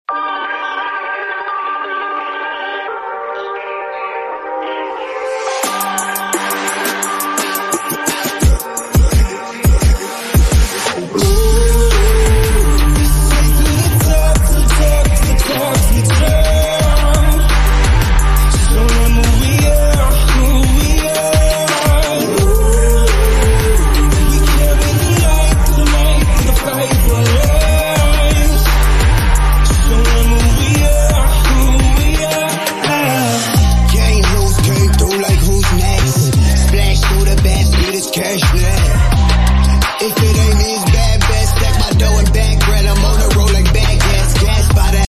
MUSIC LOBBY